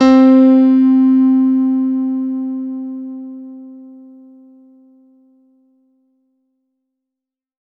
R  C3  DANCE.wav